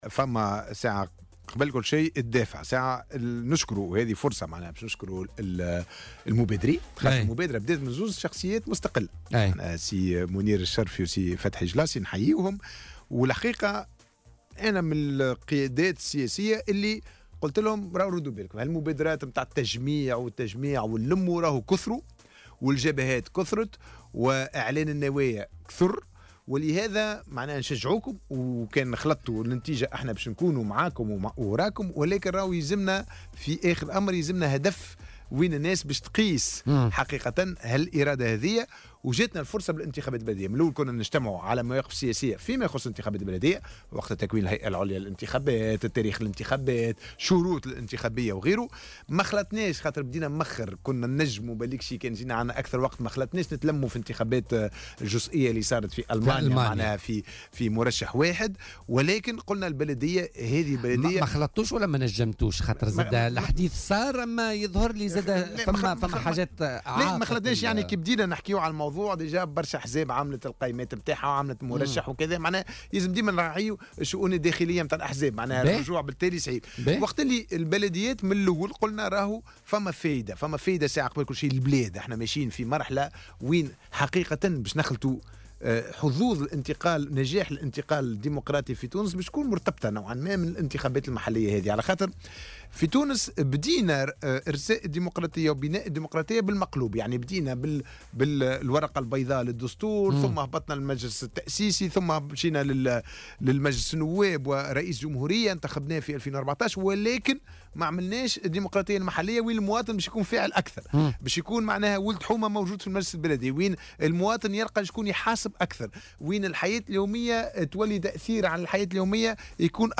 وتابع ضيف "بوليتيكا" على "الجوهرة أف أم"، ان الانتخابات البلدية سترجع الأمل للمواطن التونسي الذي سيكون في علاقة مباشرة مع الفائزين في الانتخابات البلدية وفي علاقة تشاركية معهم.